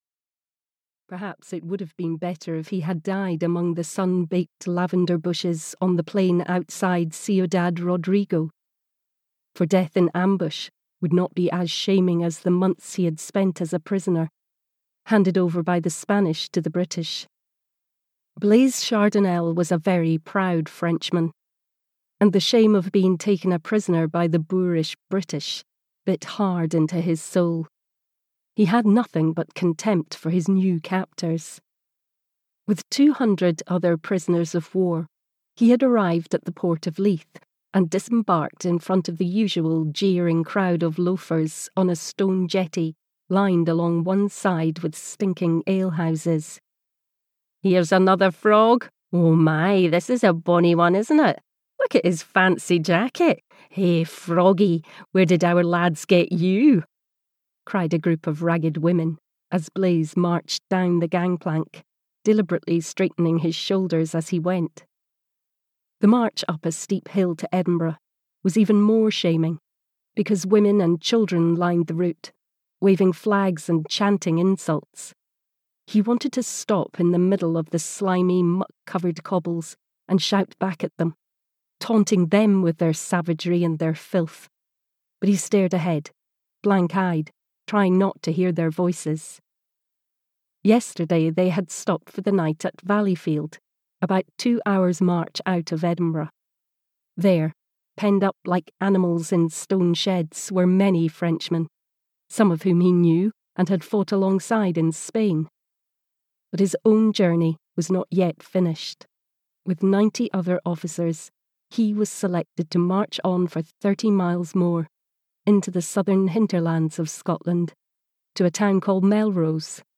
Lark Returning (EN) audiokniha
Ukázka z knihy